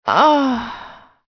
f_outch02.ogg